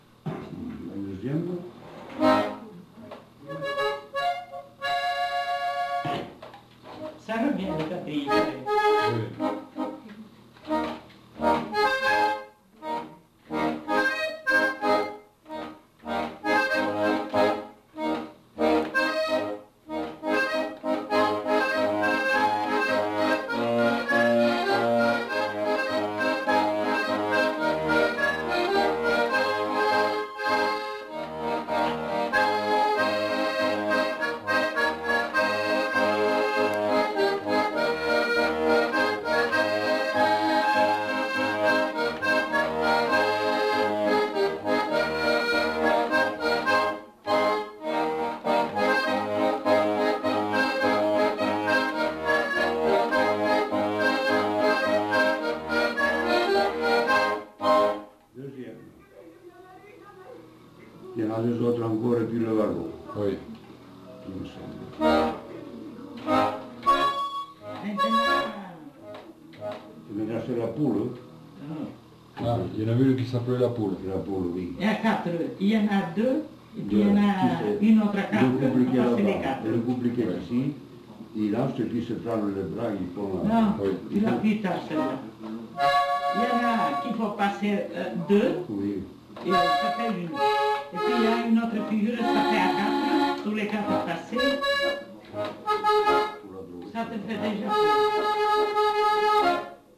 Chants et musiques à danser de Bigorre interprétés à l'accordéon diatonique
enquêtes sonores